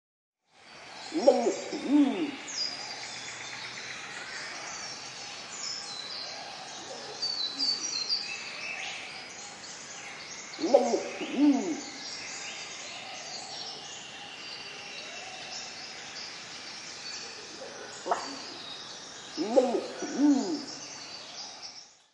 3. Wompoo Pigeon
What do I do? I call, “wallack-a-woo, wallack-a-woo”
WompooFruitDove.mp3